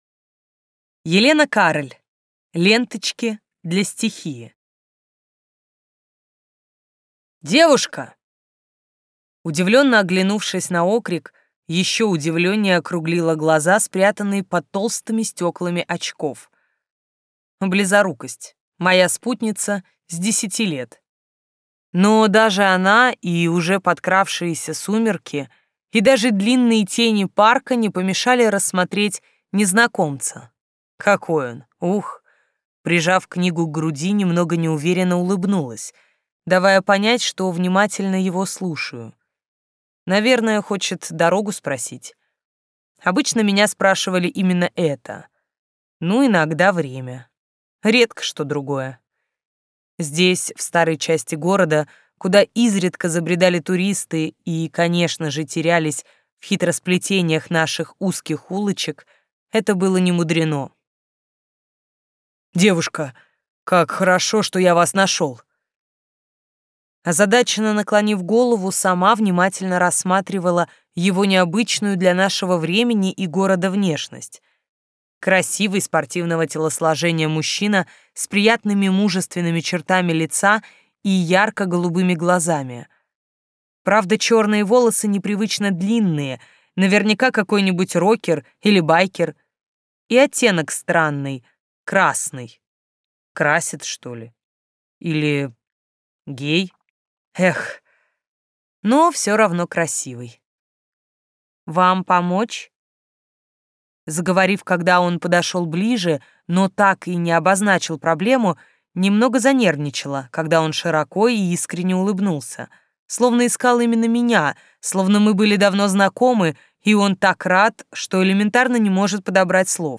Аудиокнига Ленточки для стихии | Библиотека аудиокниг